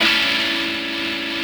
rockerPracticeChordD.wav